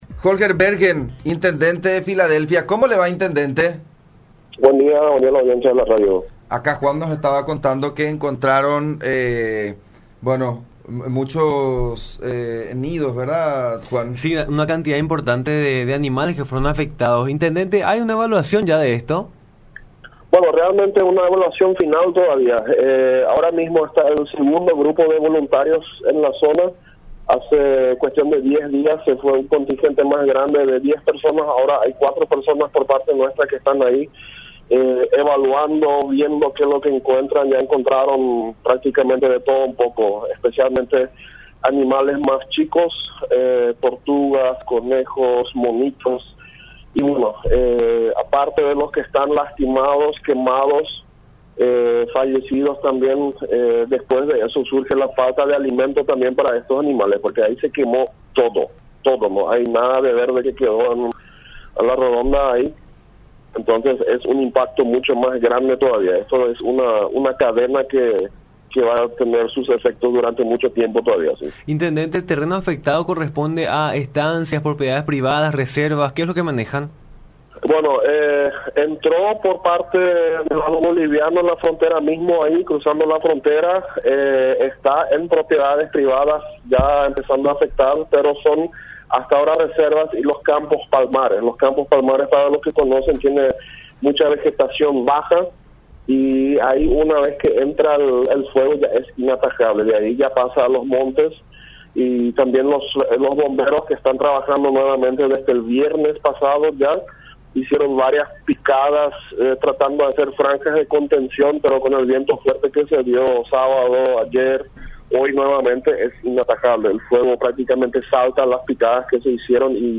“Están evaluando lo que encuentran en la zona, como tortugas, conejos, monos y otras especies”, detalló el intendente de Filadelfia, Holger Bergen, en diálogo con La Unión.
11-Holger-Bergen-Intendente-de-Filadelfia.mp3